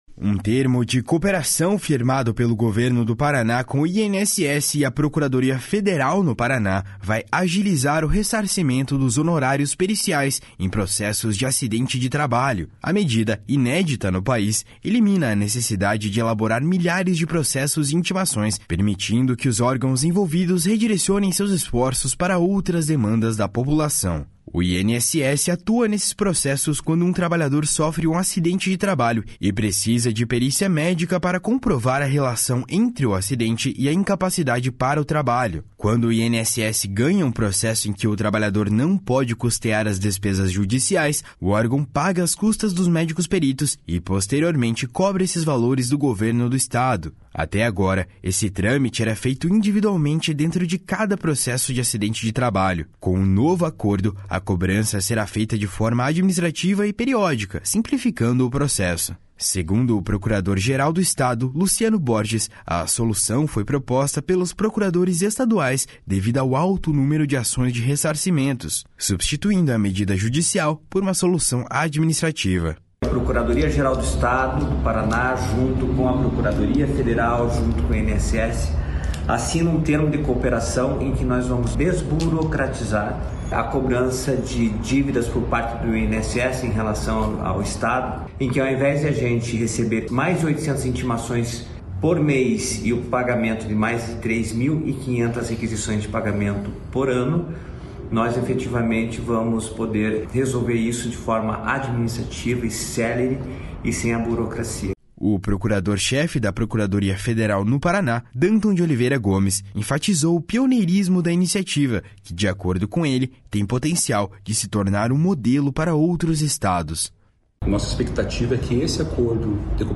Segundo o procurador-geral do Estado, Luciano Borges, a solução foi proposta pelos procuradores estaduais devido ao alto número de ações de ressarcimentos, substituindo a medida judicial por uma solução administrativa. // SONORA LUCIANO BORGES //